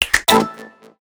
Snap.ogg